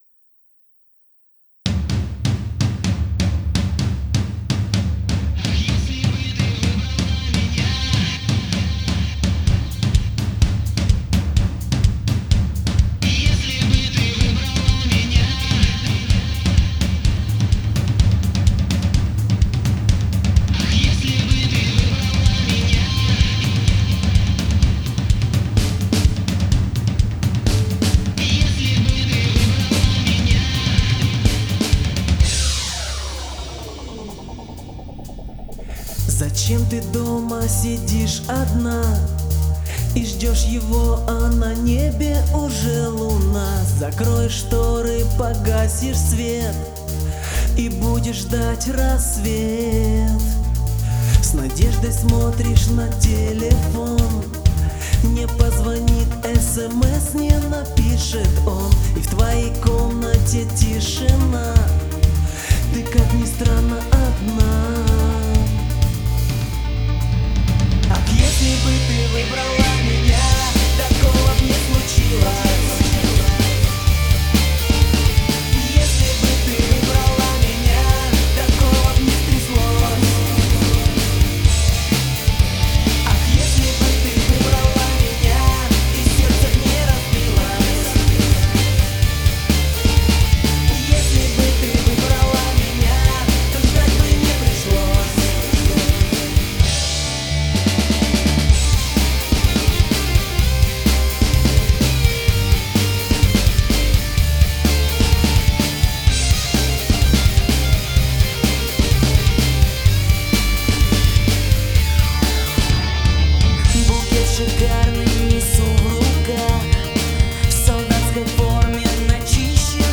Роковая версия.
Стиль: Rock